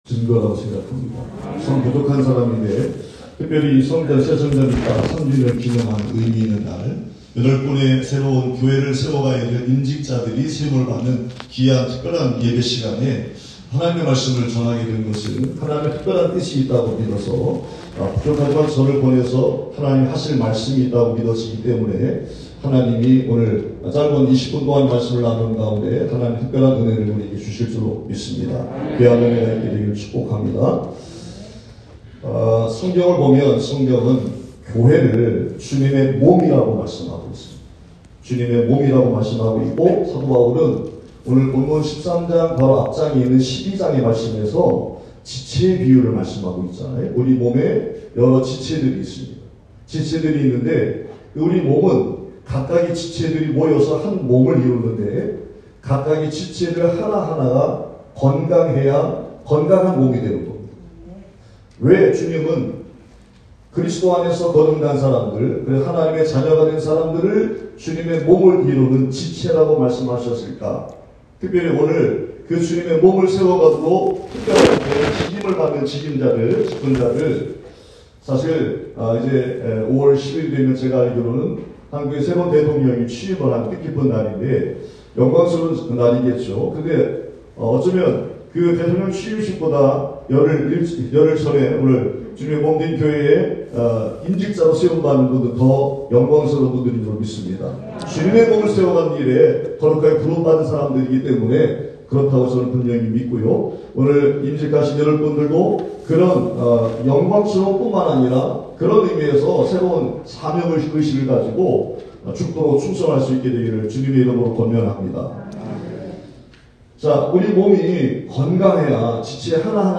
2022년 5월 1일 주일 새성전 입당 3주년
특별예배 Special Worship